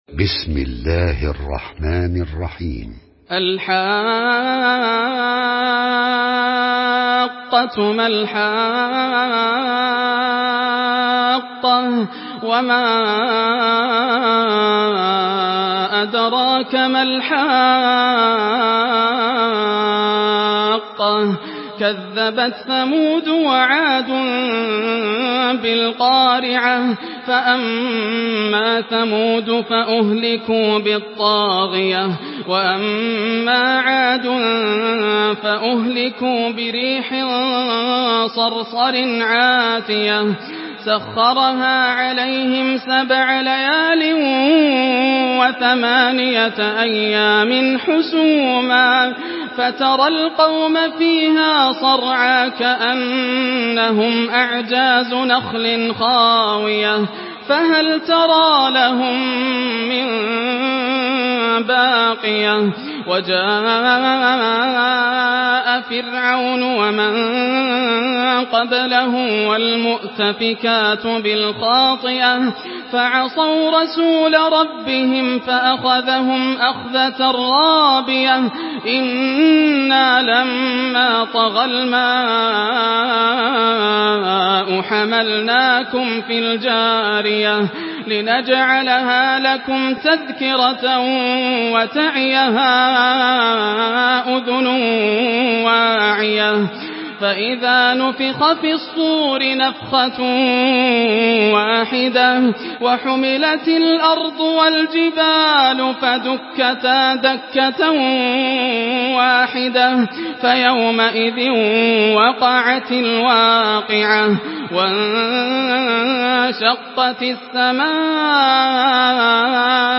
Surah Al-Haqqah MP3 in the Voice of Yasser Al Dosari in Hafs Narration
Murattal Hafs An Asim